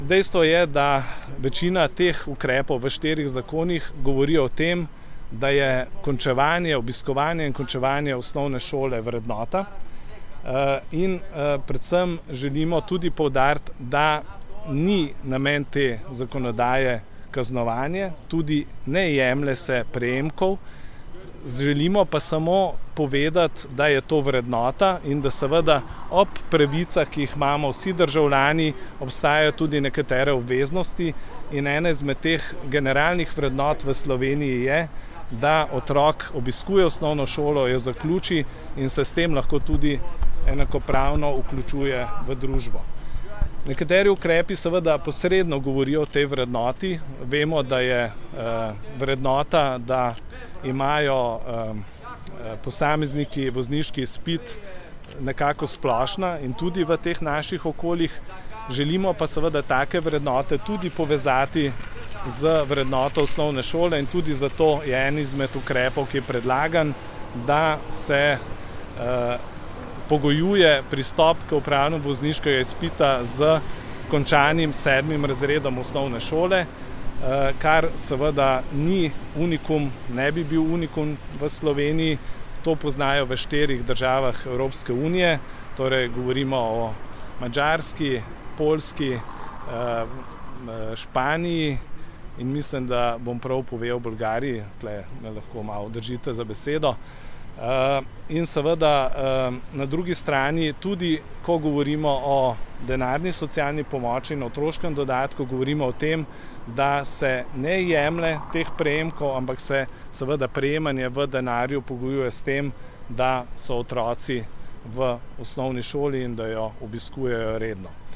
Gregor Macedoni o predlaganih spremembah